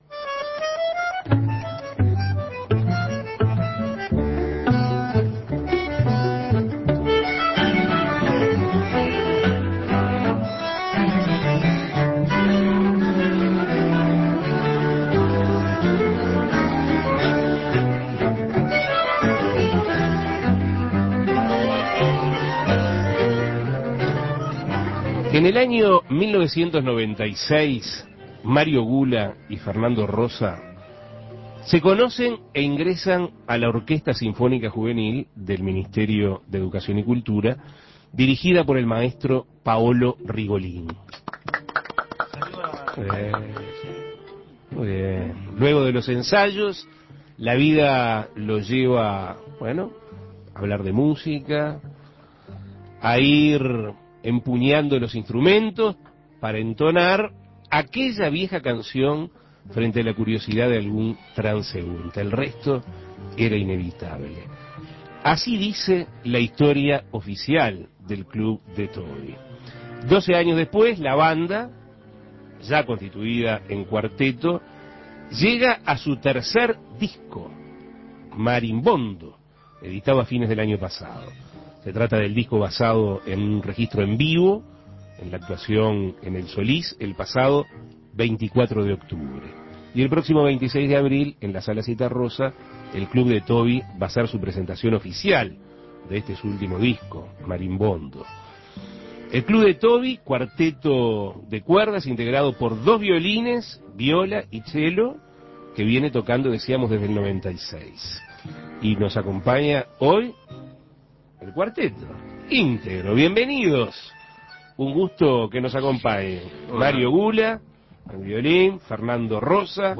Entrevistas El Club de Tobi presentó su tercer disco Imprimir A- A A+ Apasionados por la música, El Club de Tobi, un cuarteto de 12 años de trayectoria, presenta su tercer disco: Marimbondo.